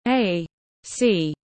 Sau công nguyên tiếng anh gọi là A.C, phiên âm tiếng anh đọc là /ˌeɪˈsiː/
A.C /ˌeɪˈsiː/
Để đọc đúng sau công nguyên trong tiếng anh rất đơn giản, các bạn chỉ cần nghe phát âm chuẩn của từ A.C rồi nói theo là đọc được ngay.